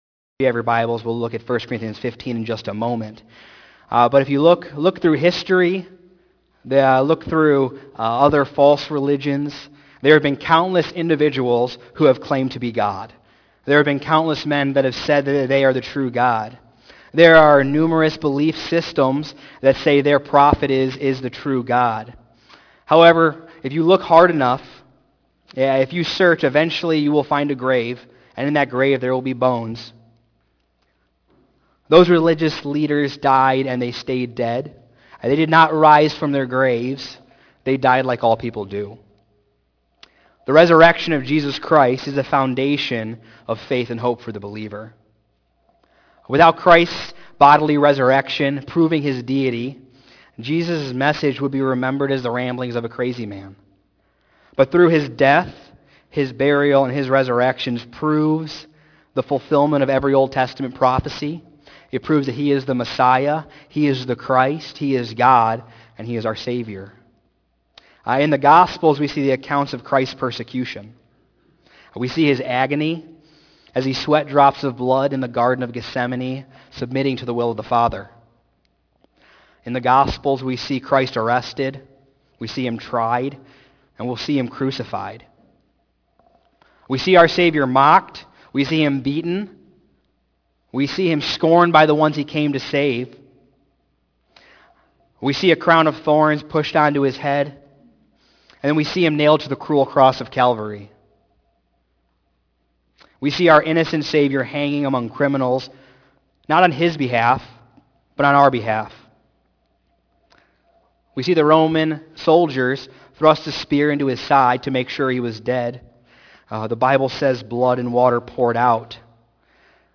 Sermons :: First Baptist of Rochelle